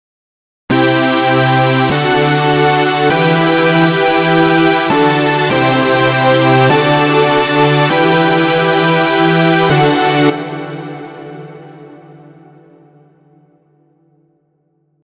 今回の解説では、Key=Gメジャー, BPM=100で進めています。
4 ) トップラインでメロディを作る
使用したシンセはSerumで、以下のようなシンプルな設定です。これにリバーブをかけています。